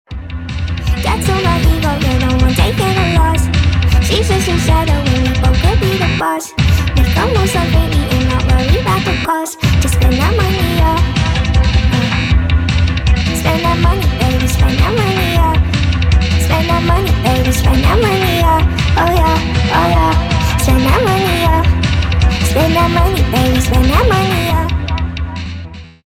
• Качество: 320, Stereo
ремиксы